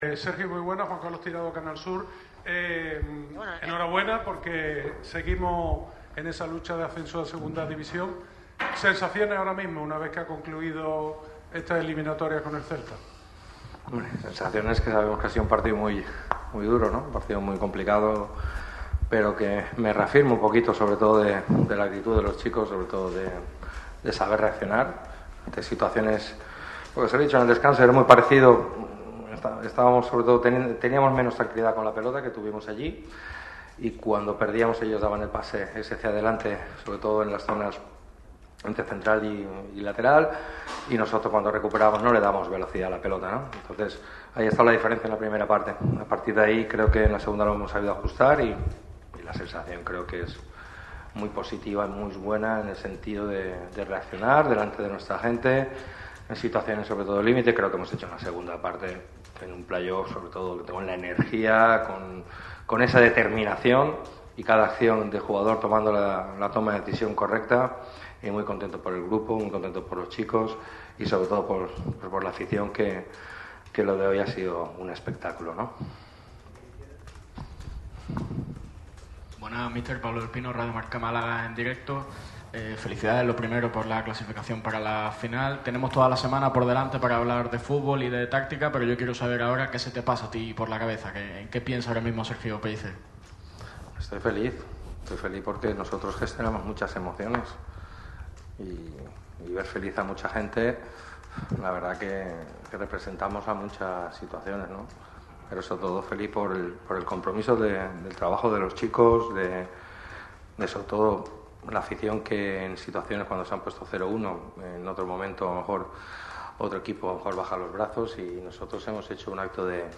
El entrenador del Málaga CF, Sergio Pellicer, ha comparecido en rueda de prensa tras conseguir el pase a la final del playoff de ascenso a LaLiga Hypermotion. El técnico de Nules ha analizado el partido, el trabajo del equipo y ha dejado varios titulares.